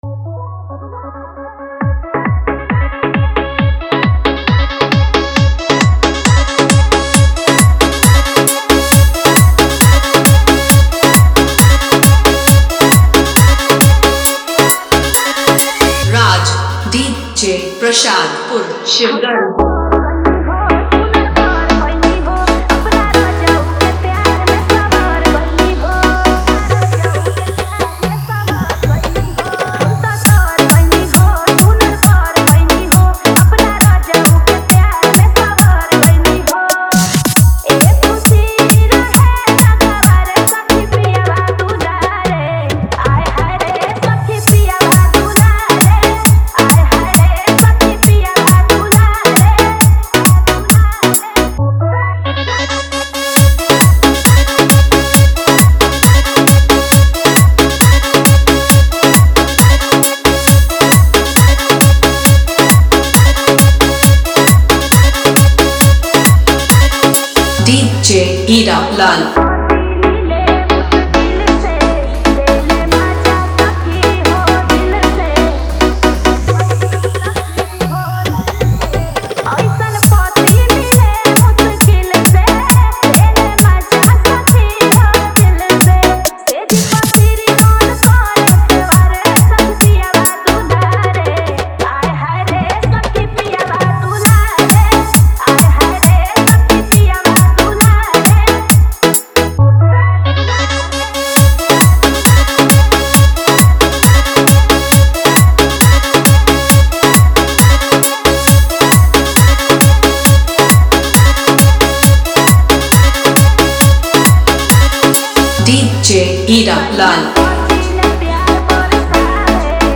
Bhojpuri Remix